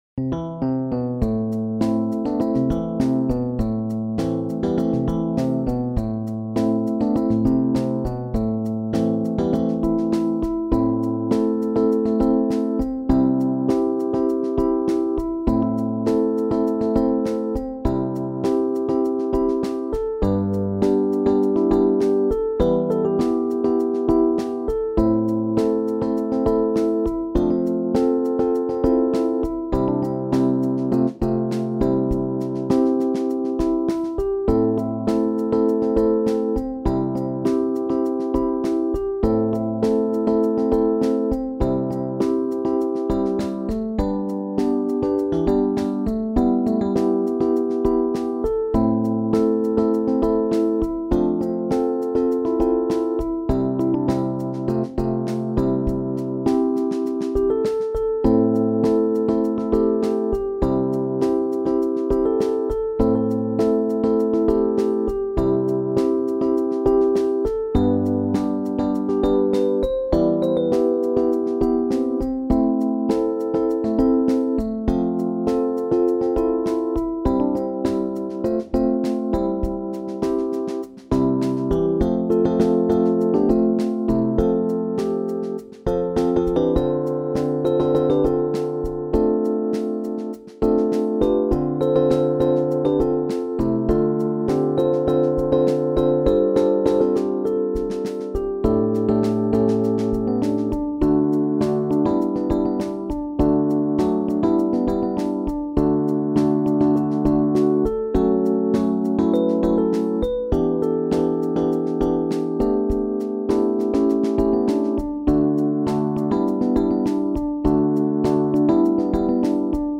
SSAAB | SSATB
in een gospelachtige bewerking